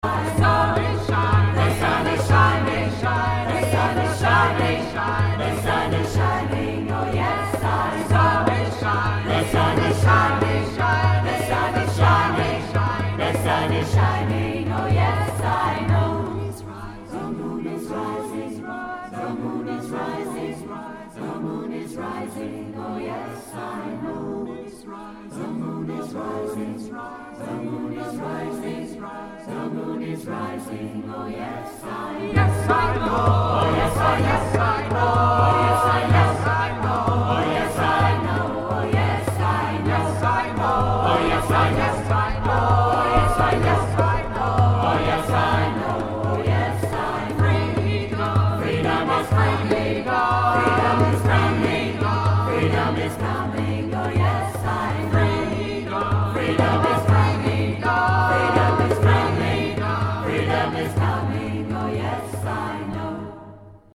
Trad African